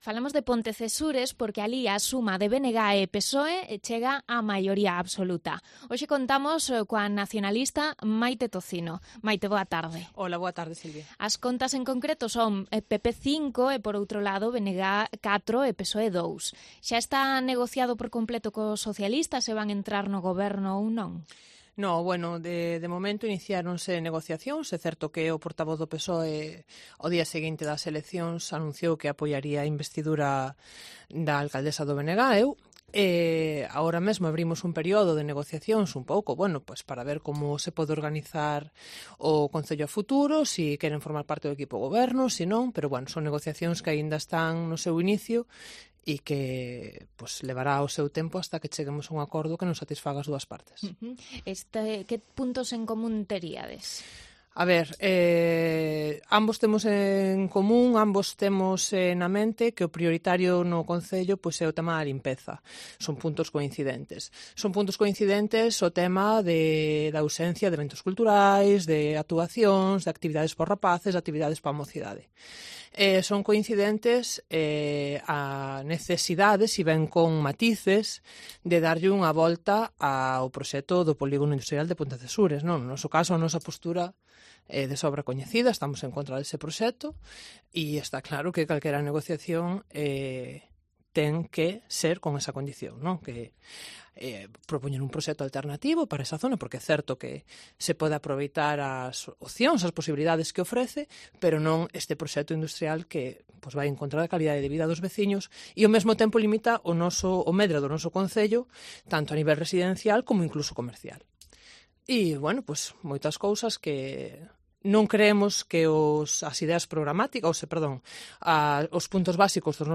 AUDIO: Entrevista a Maite Tocino, futura alcaldesa de Pontecesures por el BNG si se confirma el apoyo del PSOE en la investudura del 17 de junio.